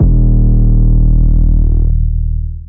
ART - 808 (GOOSEBUMPS).wav